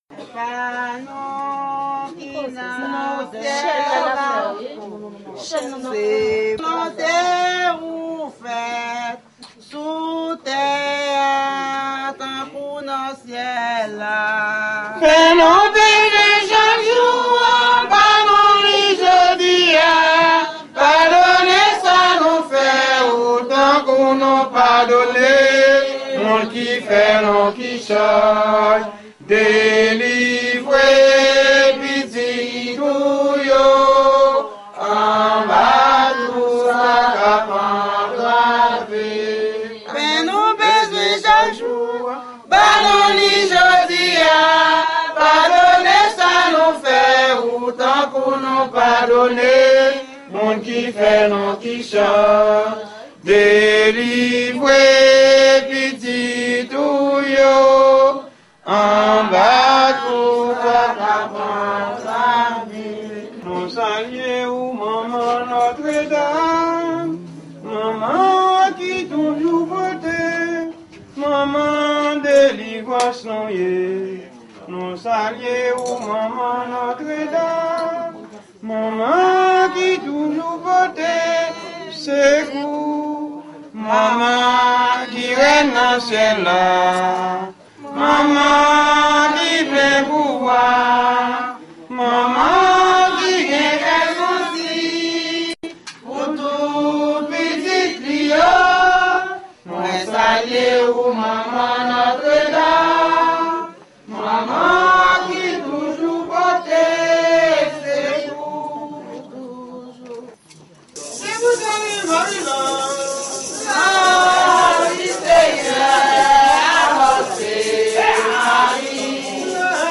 prière ordinaire
x-prye-live-mwen-salue-manma-notre-dame.mp3